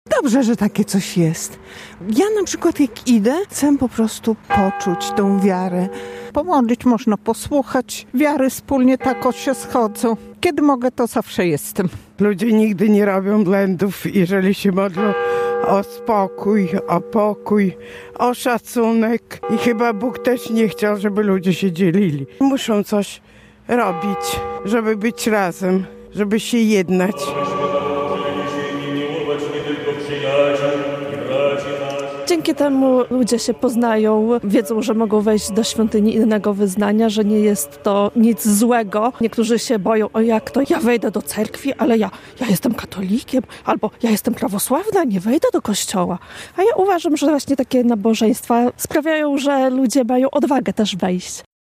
Niektórzy się boją, jak mam wejść do cerkwi skoro jestem katolikiem, albo jestem prawosławna, nie wejdę do kościoła, uważam, że takie nabożeństwa sprawiają, że ludzie mają odwagę wejść - mówią wierni.